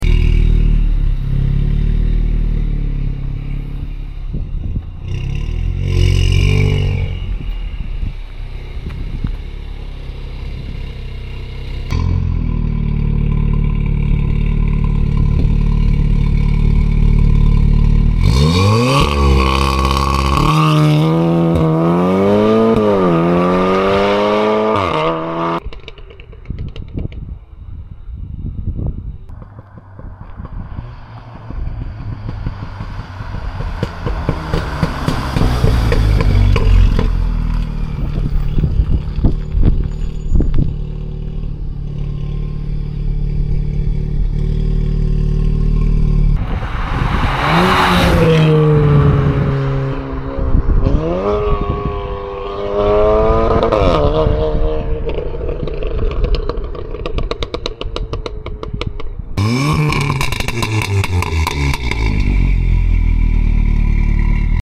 Stage 1 Launch Control On Sound Effects Free Download